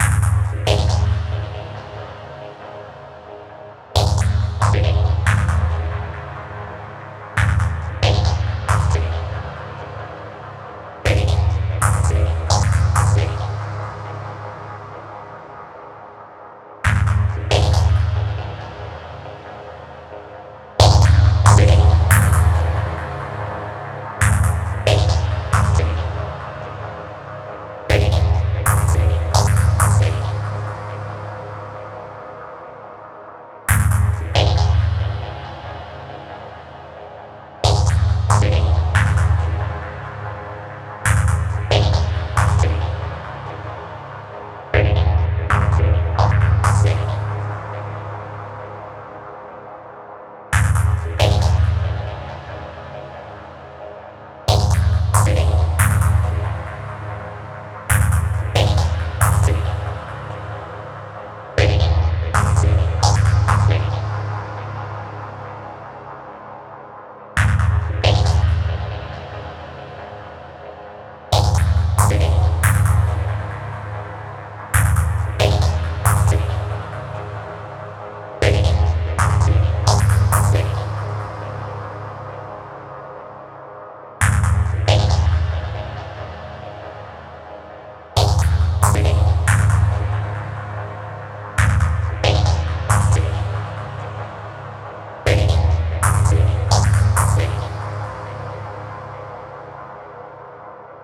Could listen to continuous minor chords forever X)
Gritty and squelchy, perfect combination :+1: